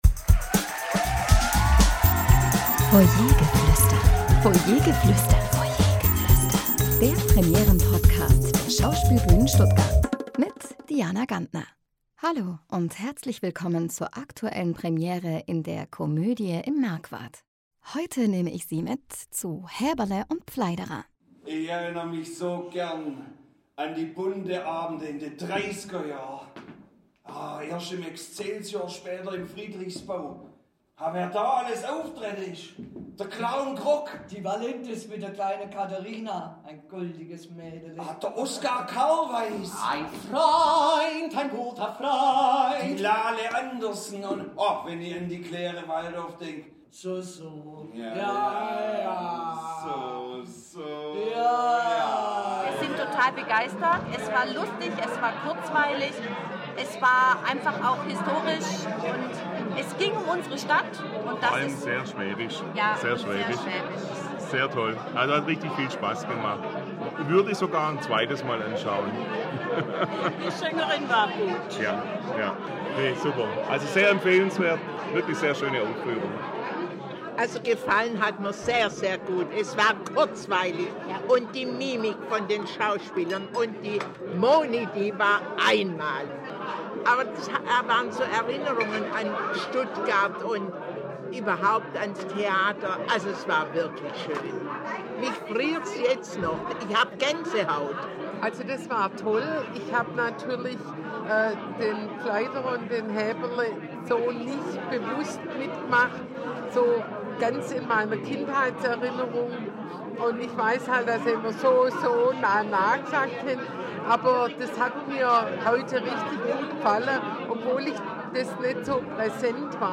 Zuschauerstimmen zur Premiere von “Häberle und Pfleiderer”